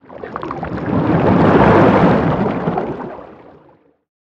Sfx_creature_hiddencroc_swim_slow_04.ogg